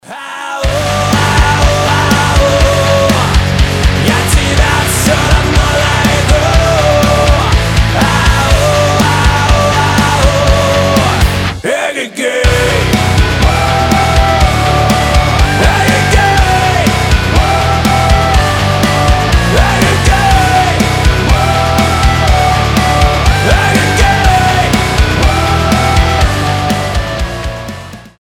громкие
Драйвовые
Cover
Alternative Metal
Mashup
Industrial metal